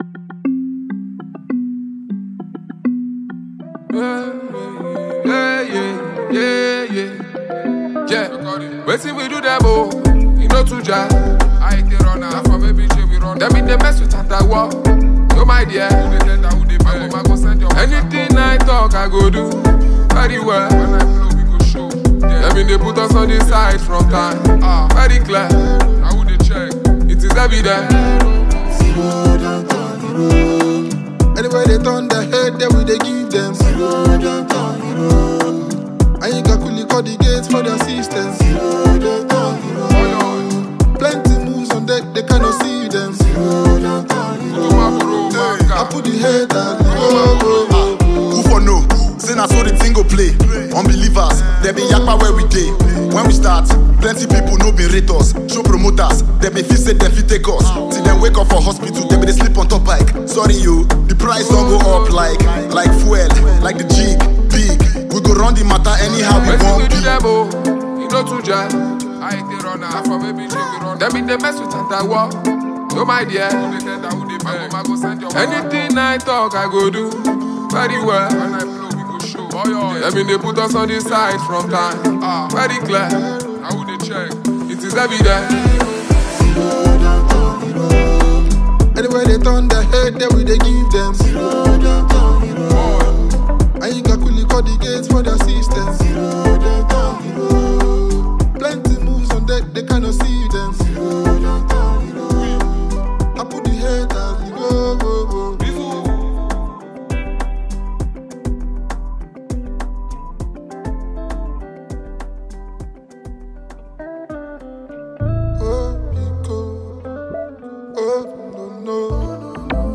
Nigerian Music
Rap